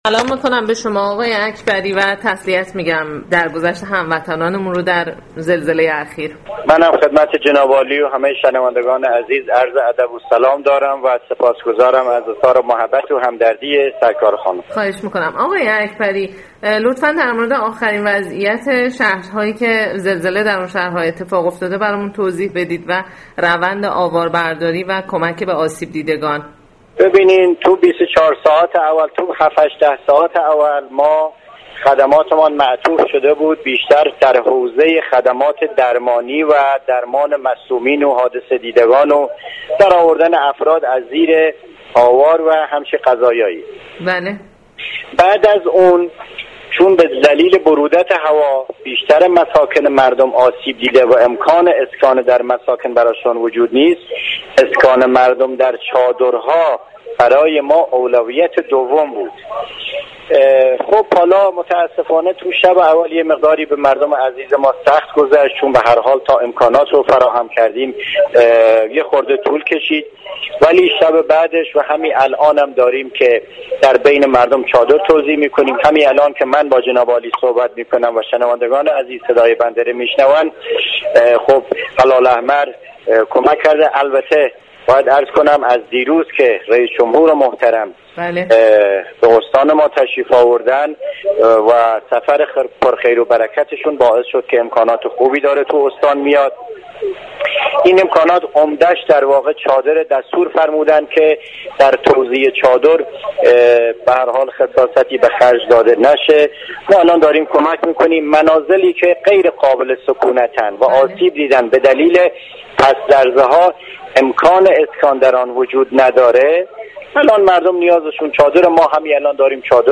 گفتگوی اختصاصی سایت رادیو فرهنگ با فرامرز اكبری فرماندار قصر شیرین